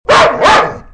Cane grosso che abbaia
Due abbaiare di un bel cane grosso e arrabbiato.
LOUDDOG.mp3